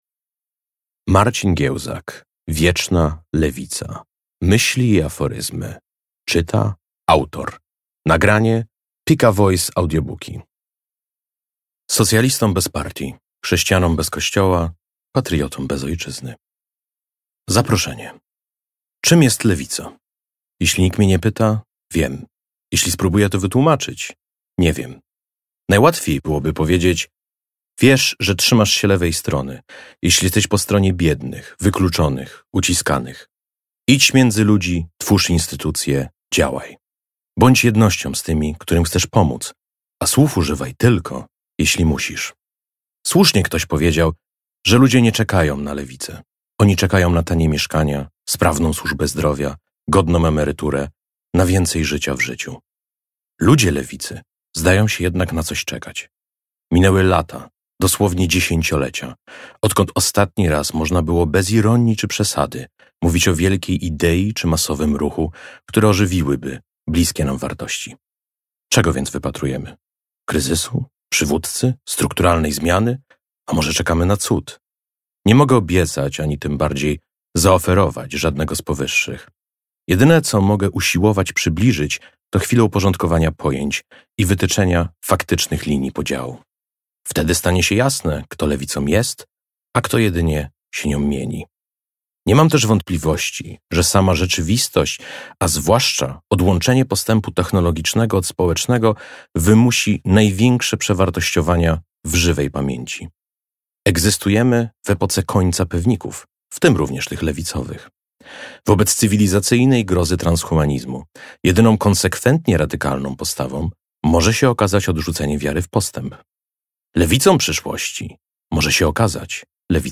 Wieczna lewica. Myśli i aforyzmy - sprawdź i kup audiobook. Lewica nie powinna być ideologią, zbiorem doktryn ani partią polityczną.